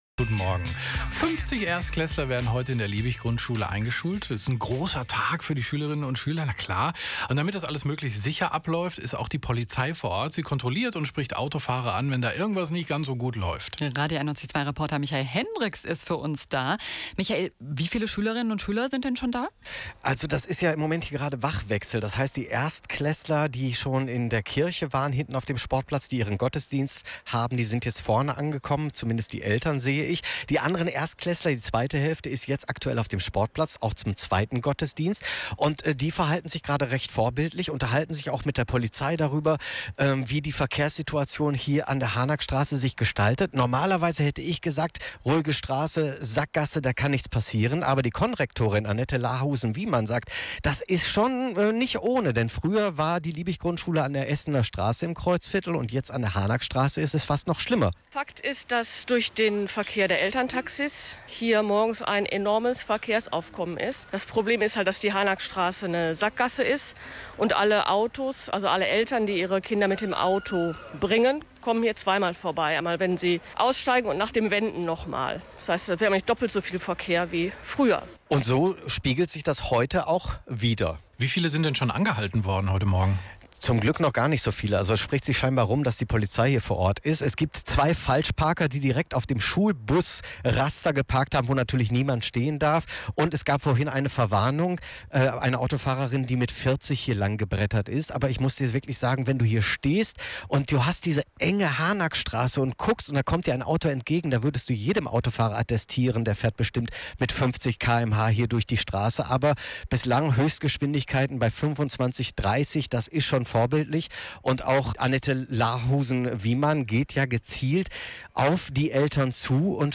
Der Radiobeitrag zum Nachhören